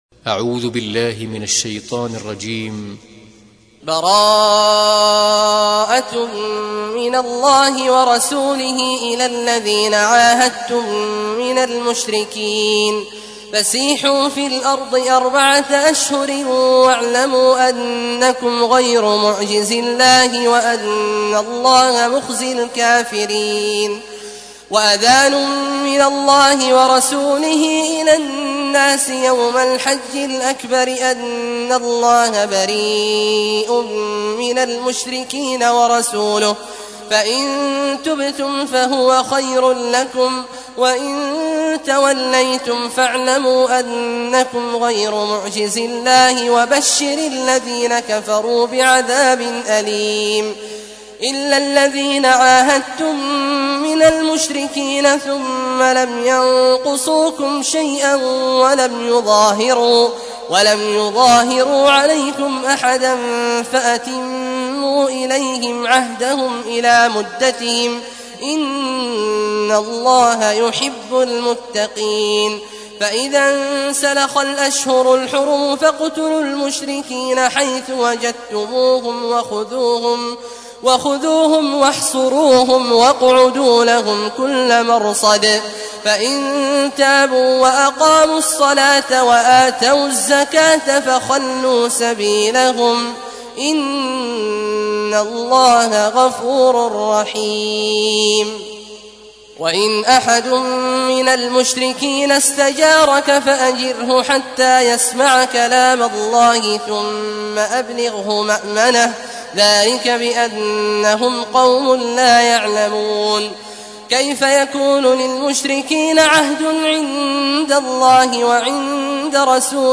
تحميل : 9. سورة التوبة / القارئ عبد الله عواد الجهني / القرآن الكريم / موقع يا حسين